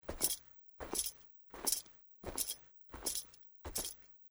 带有金属链的皮靴在地上行走-YS070525.mp3
通用动作/01人物/01移动状态/木质地面/带有金属链的皮靴在地上行走-YS070525.mp3